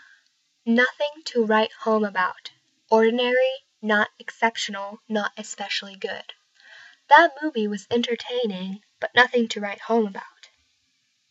英語ネイティブによる発音はこちらです。